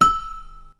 • Bell Lisesi
bell_thud_higher_pitch.wav